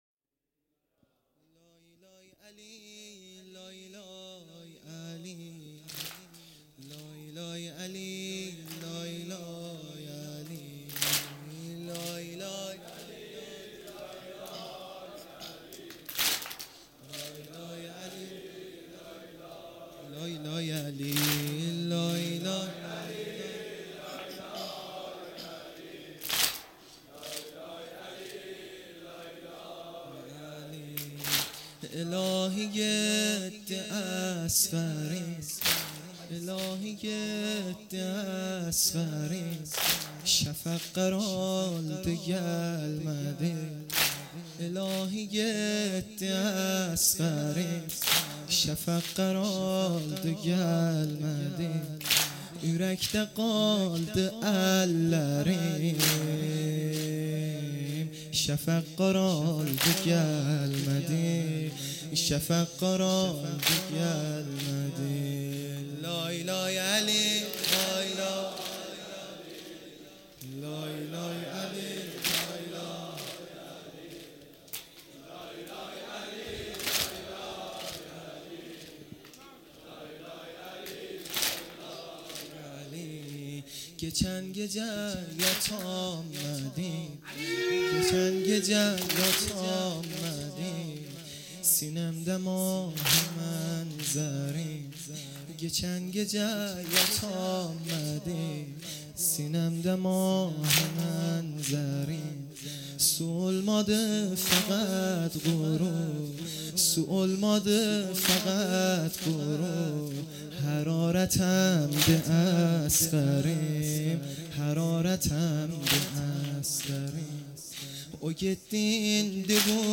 سنگین سنتی ا لای لای علی